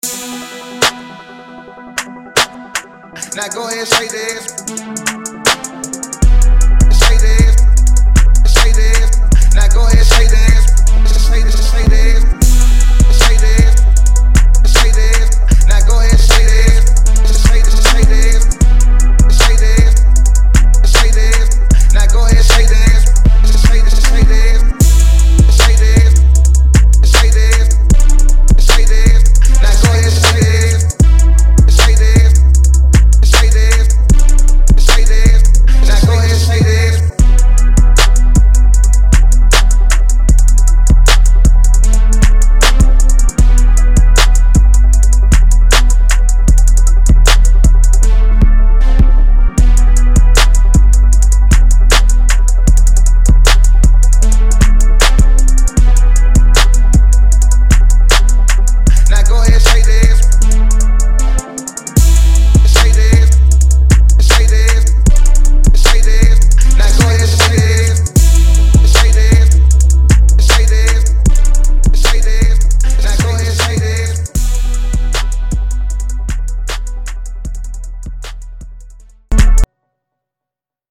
Miami Club Type Beats
Hip Hop Industry Type Rap Beat